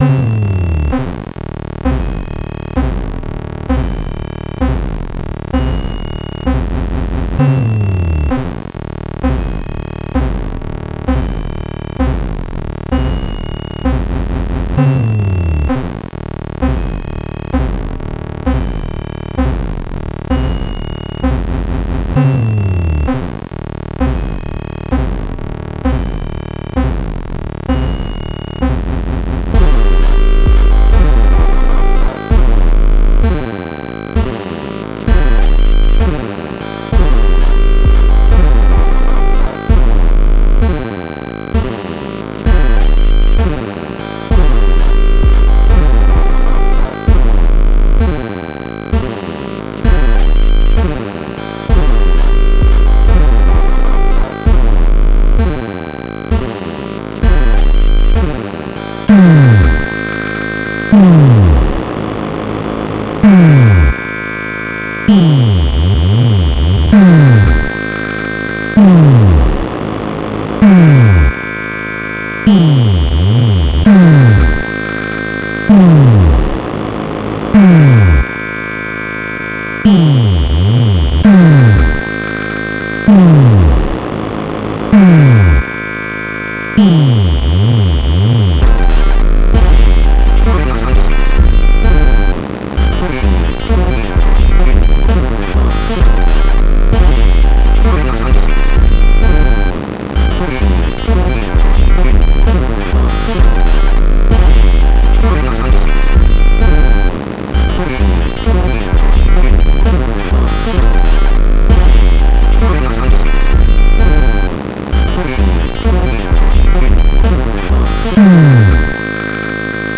Here you can listen to my first recording - a jam - with 3 ATPC (ATiny Punk Console) by Noisio last October during my stay at Circuit Control in Dresden, Germany. At the end of the tune (starting 3:27) I added some notes with the LinPlug soft synth "Spectral".
Used sounds from left to right: Fail_U_03, Sweep_02 and ATPC_01.